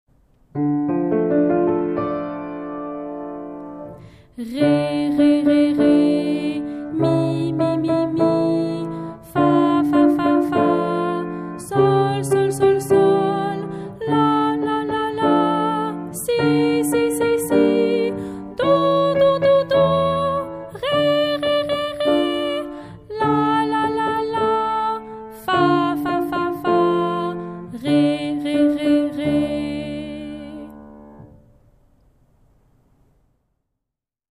Audio voix et piano (chanson complète)